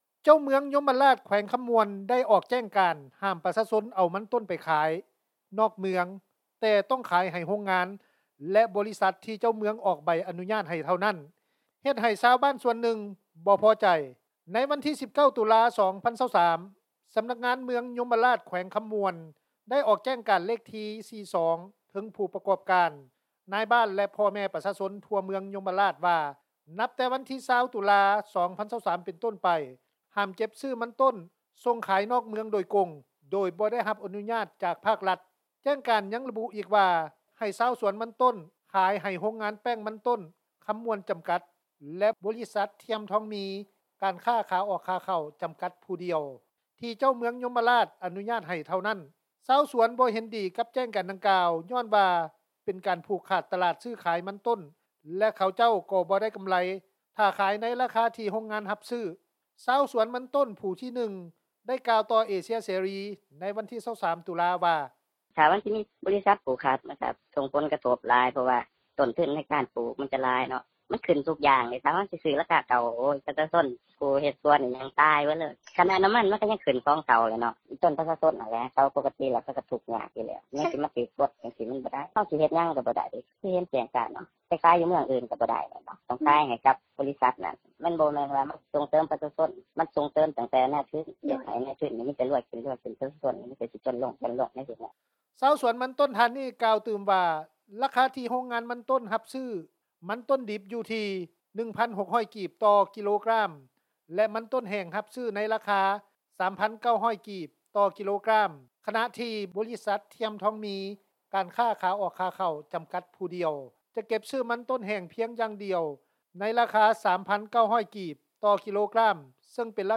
ຊາວສວນມັນຕົ້ນ ຜູ້ທີ 1 ໄດ້ກ່າວຕໍ່ວິທຍຸ ເອເຊັຽເສຣີ ໃນວັນທີ 23 ຕຸລາ ວ່າ:
ຊາວສວນມັນຕົ້ນຜູ້ທີ 2 ໄດ້ກ່າວວ່າ:
ຜູ້ປະກອບການ ຮັບຊື້ມັນຕົ້ນ ຢູ່ແຂວງໄຊຍະບູລີ ໄດ້ກ່າວວ່າ: